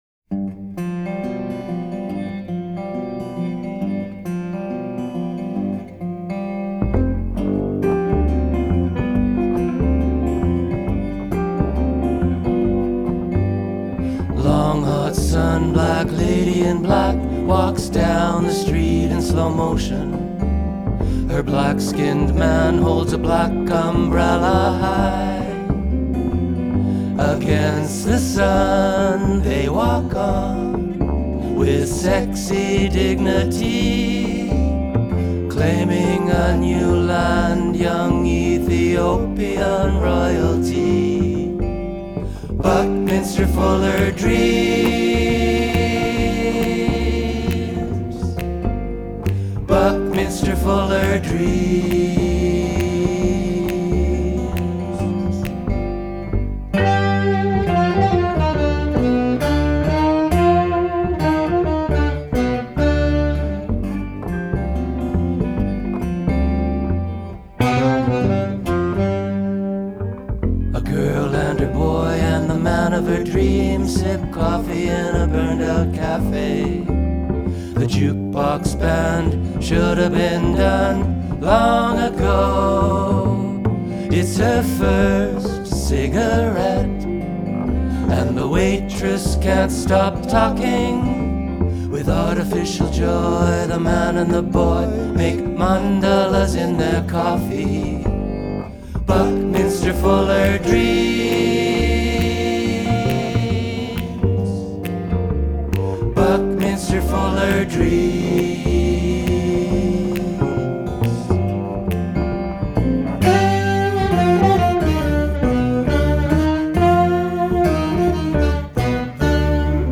Recorded live at the No Fun Club in Winnipeg
acoustic guitar/vocals
electric guitar/vocals
sax/vocals
bass/vocals
fingersnaps